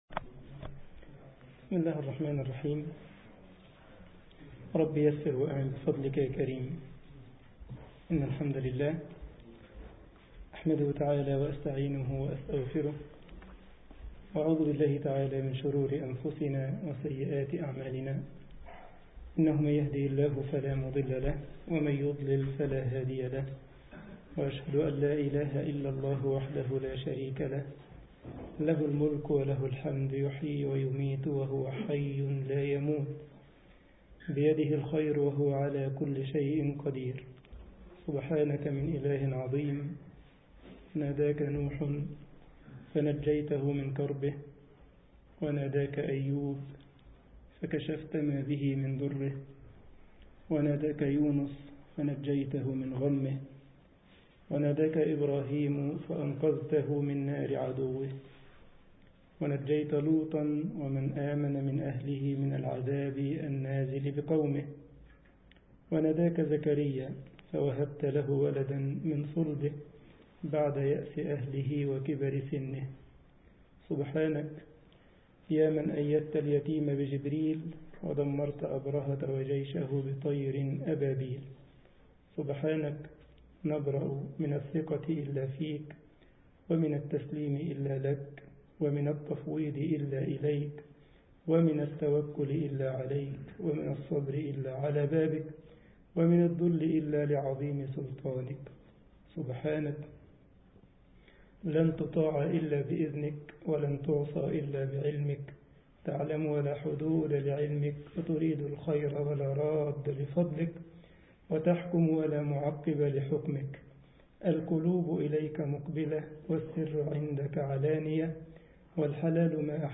مسجد الجمعية الإسلامية بكايزرسلاوترن ـ ألمانيا درس